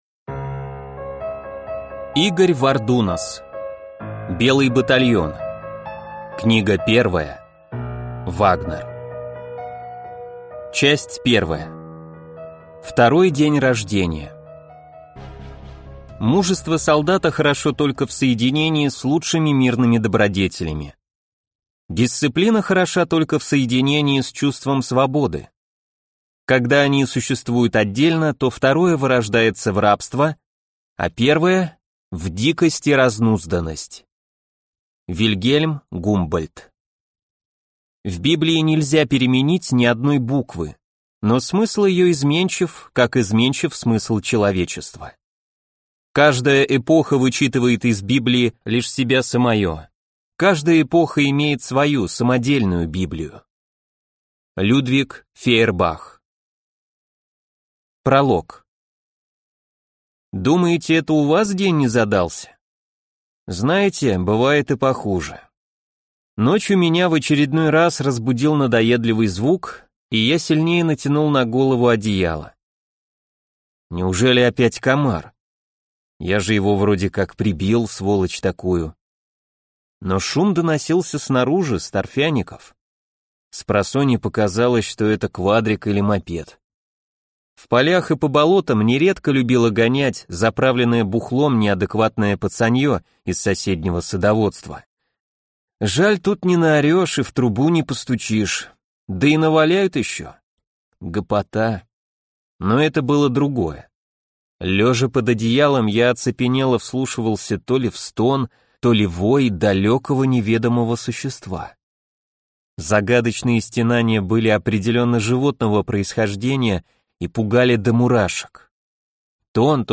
Аудиокнига Вагнер | Библиотека аудиокниг